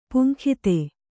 ♪ puŋkhite